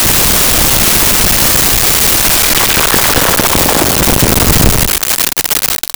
Explosion Medium 3
Explosion_Medium_3.wav